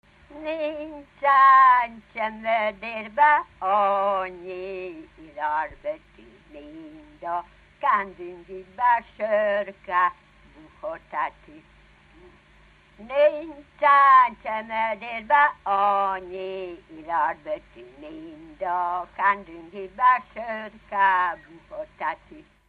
Dunántúl - Zala vm. - Nova
ének
Stílus: 6. Duda-kanász mulattató stílus
Szótagszám: 6.6.6.6
Kadencia: 5 (1) 4 1